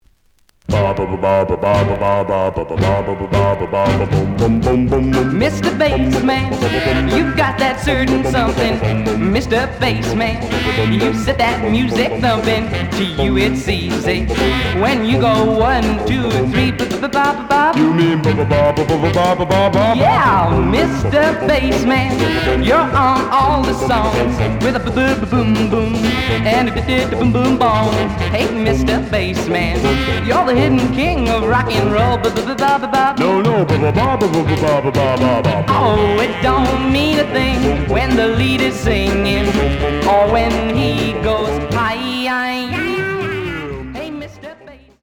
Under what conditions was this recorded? The audio sample is recorded from the actual item. Slight edge warp.